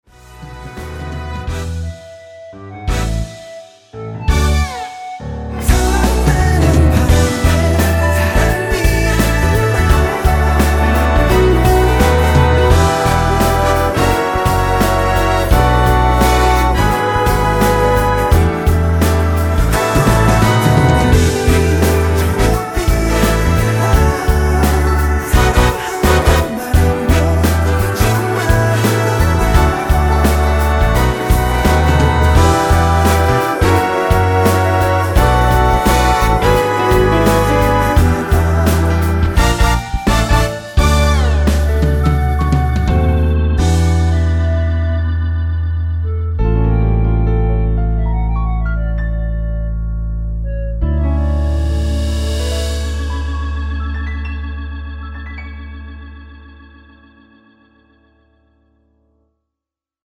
2분56초 부터 10초 정도 보컬 더블링 된 부분은 없습니다.(미리듣기 확인)
원키 멜로디와 코러스 포함된 MR입니다.
Db
앞부분30초, 뒷부분30초씩 편집해서 올려 드리고 있습니다.
중간에 음이 끈어지고 다시 나오는 이유는